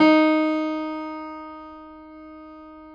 53h-pno11-D2.aif